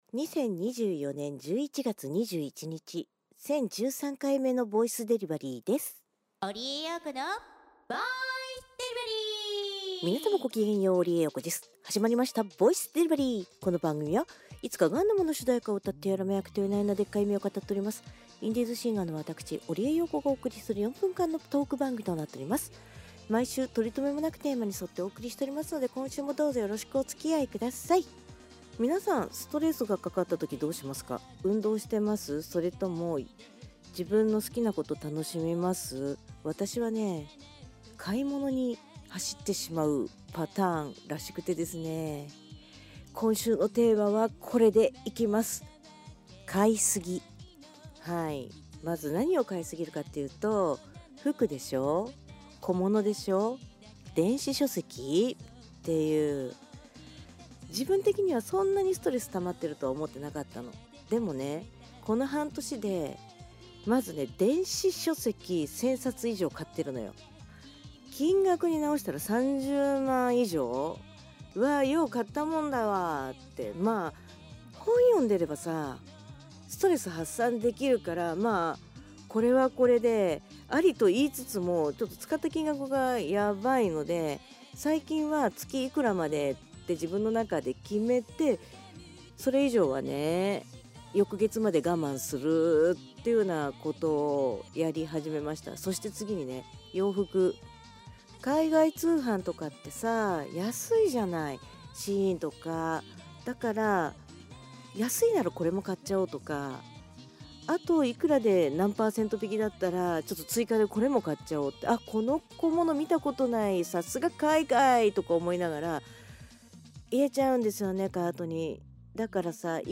再生できない場合、ダウンロードは🎵こちら 年末が近づき増えすぎた諸々を眺めつつ、自戒を込めて買いすぎたものについてトークします！ 毎週水曜日か木曜日更新の４分間のトーク番組。日常の出来事をとりとめとなくおしゃべりしています。